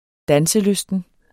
Udtale [ -ˌløsdən ]